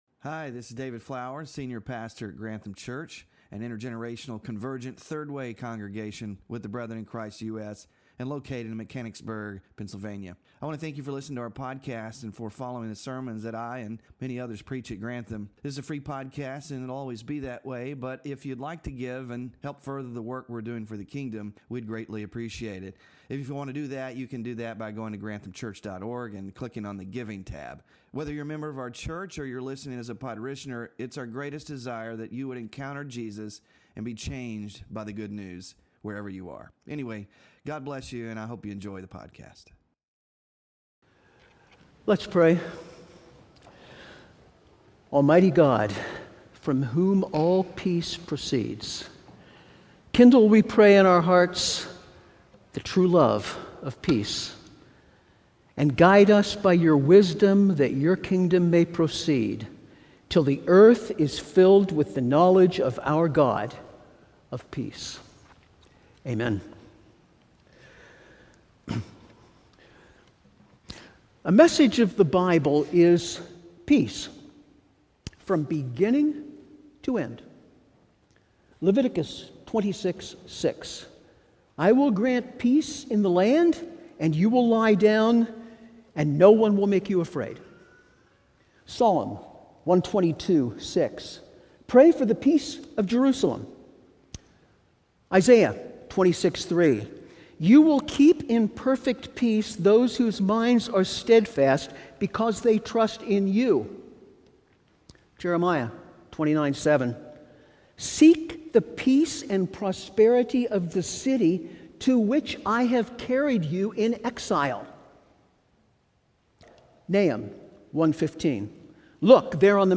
FRUIT OF THE SPIRIT WK 3-PEACE SERMON SLIDES